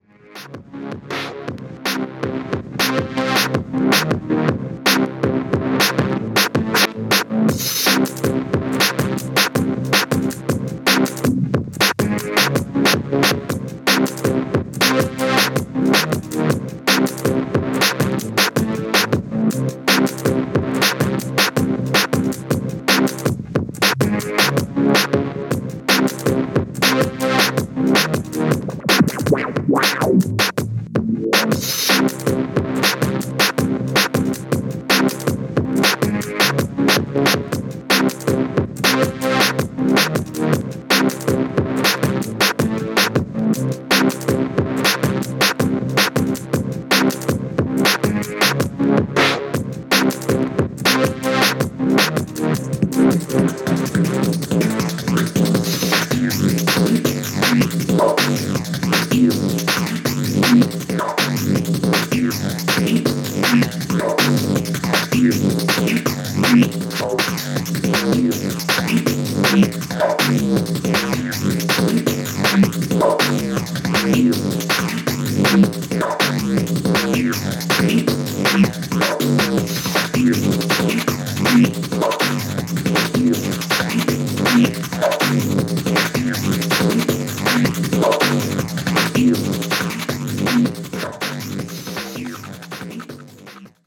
ノイジーなサウンドがハマります（笑）